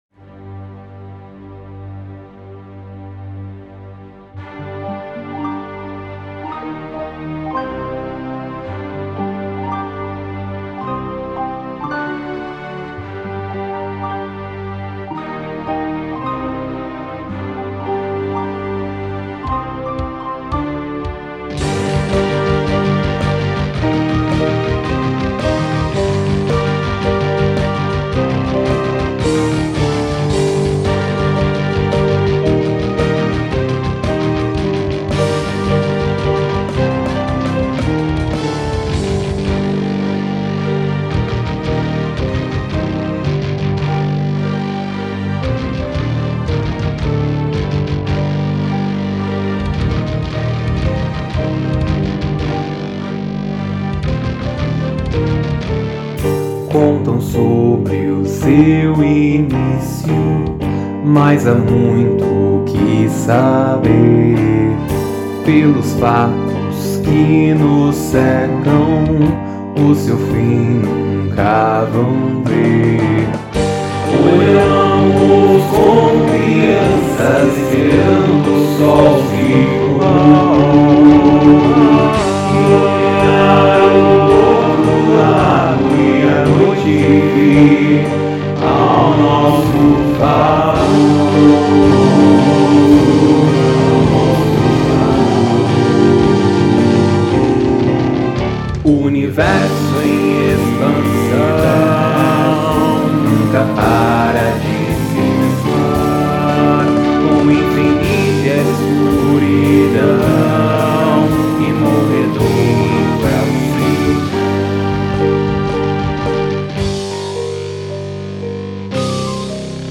EstiloInstrumental